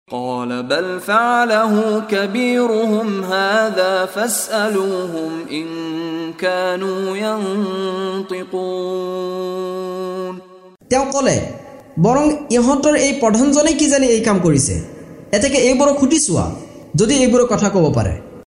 অসমীয়া ভাষাত (ছুৰা আল-আম্বিয়া)ৰ অৰ্থানুবাদৰ অডিঅ ৰেকৰ্ডিং।
লগতে ক্বাৰী মিশ্বাৰী ৰাশ্বিদ আল-আফাছীৰ কণ্ঠত তিলাৱত।